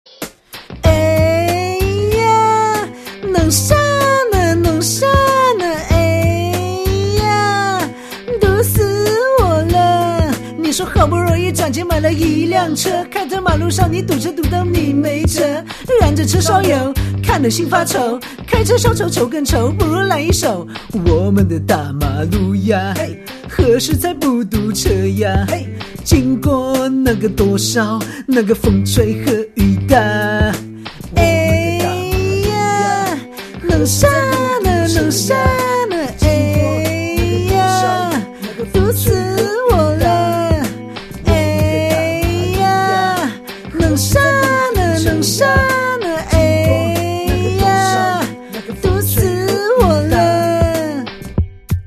搞笑铃声